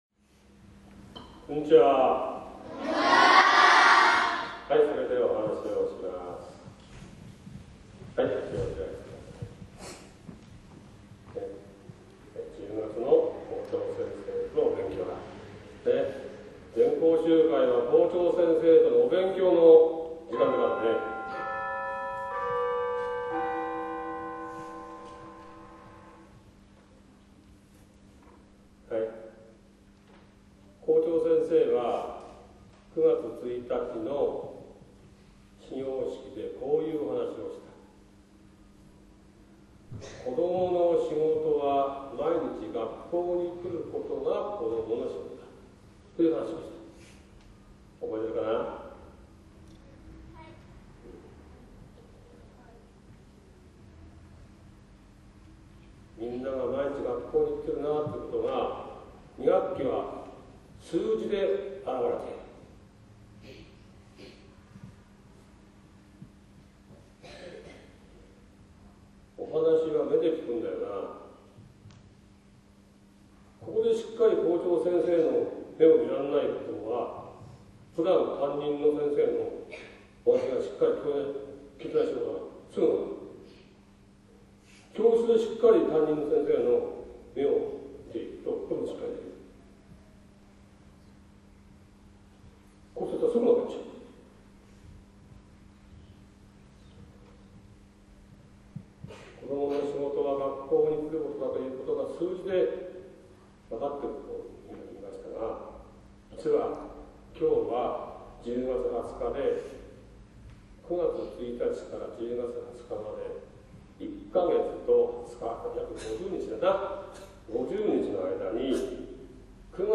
１０月の全校集会で、
（7分35秒・ステレオ）